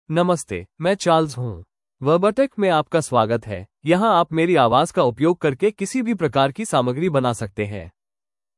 CharlesMale Hindi AI voice
Charles is a male AI voice for Hindi (India).
Voice sample
Listen to Charles's male Hindi voice.
Male
Charles delivers clear pronunciation with authentic India Hindi intonation, making your content sound professionally produced.